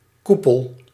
Ääntäminen
IPA: /ly.kaʁn/